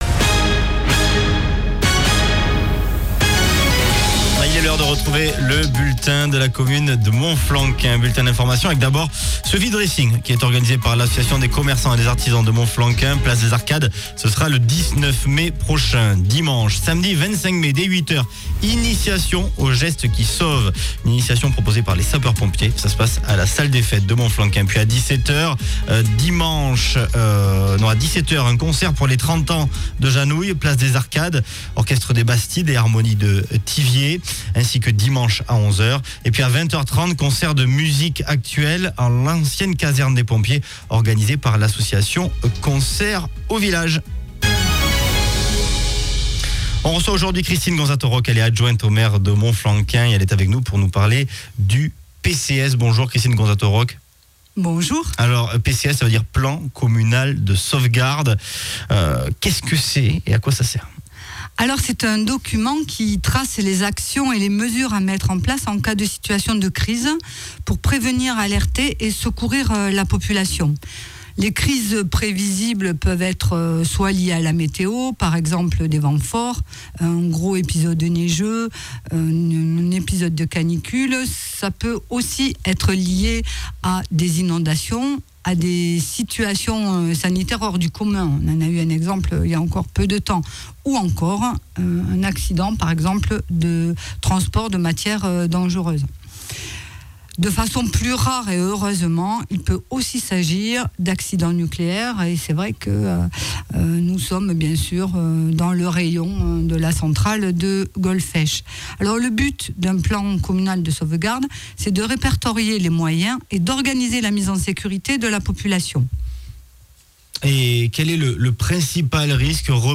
VOS ÉLU(E)S  à la RADIO